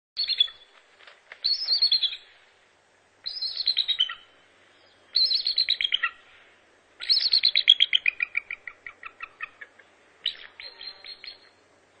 American Bald Eagle
Bird Sound
Call high-pitched whistling or piping.
BaldEagle.mp3